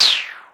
Shoot20.wav